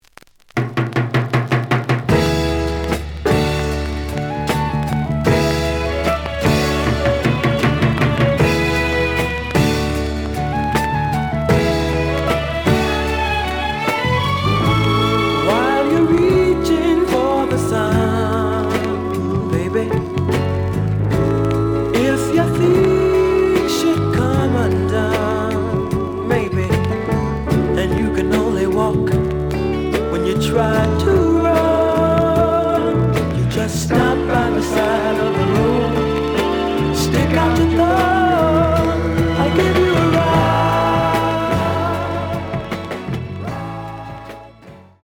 ●Format: 7 inch
●Genre: Soul, 70's Soul